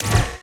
SCIMisc_Sci Fi Shotgun Reload_01_SFRMS_SCIWPNS.wav